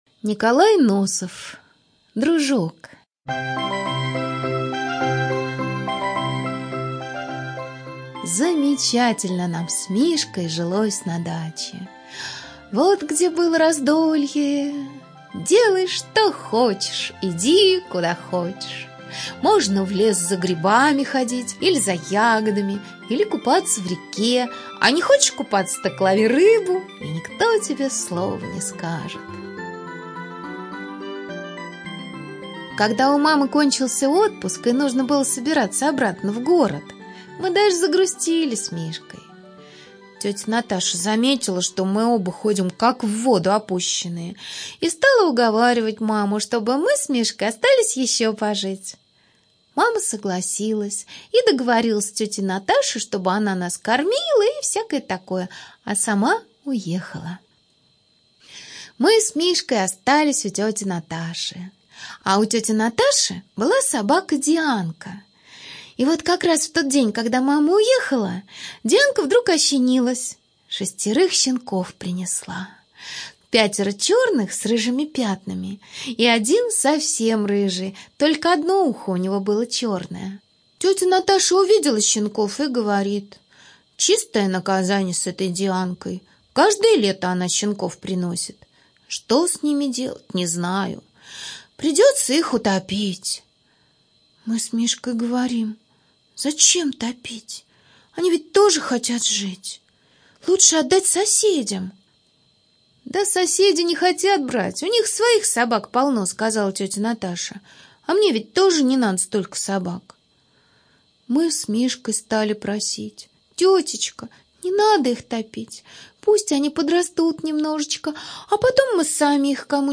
Студия звукозаписиРадио Р21